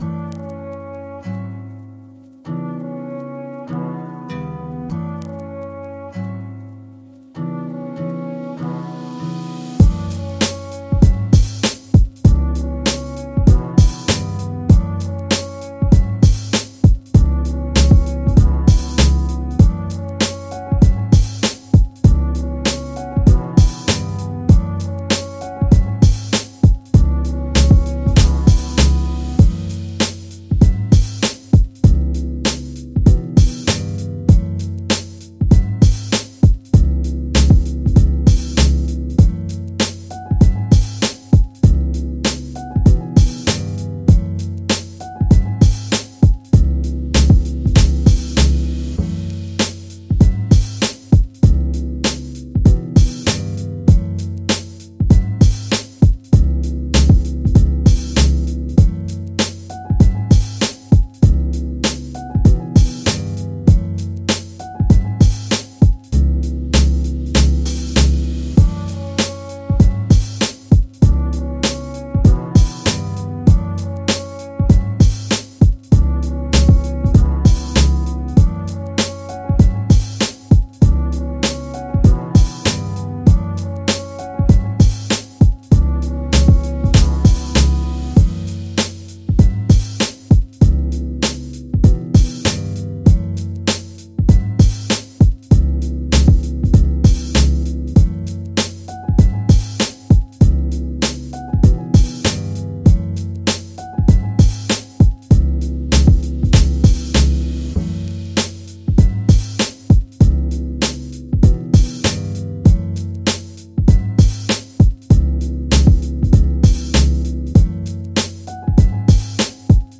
Красивые инструментальные биты для рэпа